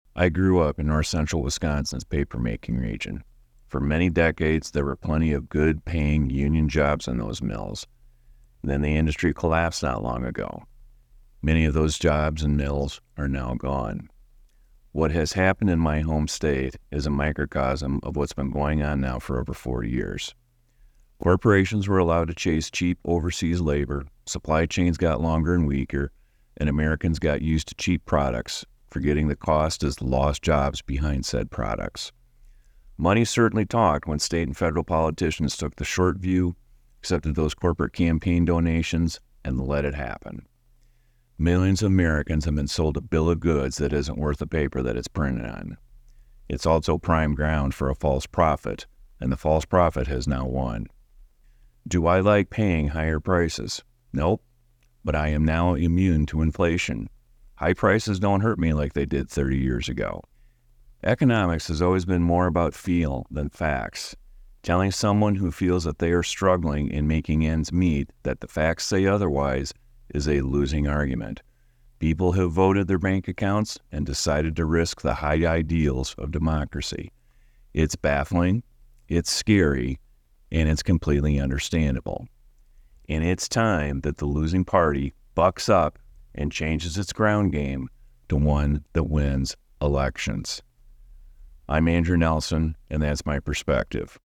Perspectives are commentaries produced by and for WNIJ listeners, from a panel of regular contributors and guests.